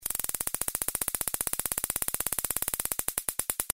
Звуки электрошокера
На этой странице собраны звуки электрошокера – от резких разрядов до характерного жужжания.